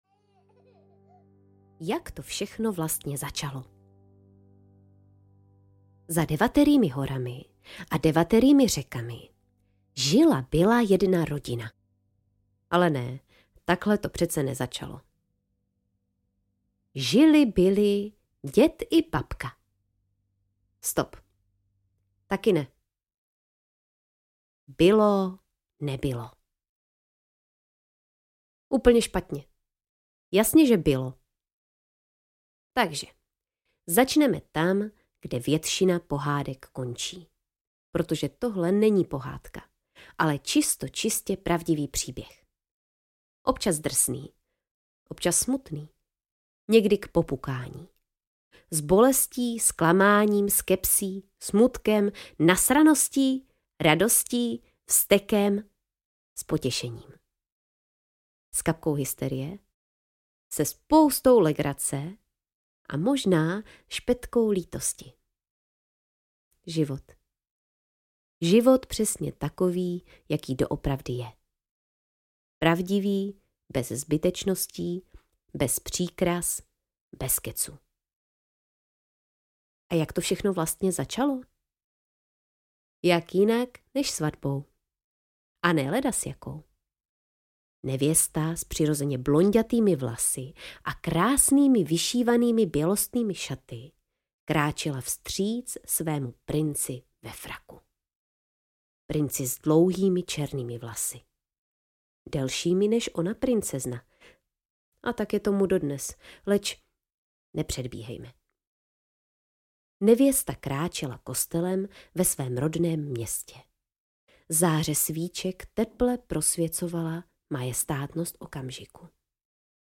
Kronika rodu Příšeráků audiokniha
Ukázka z knihy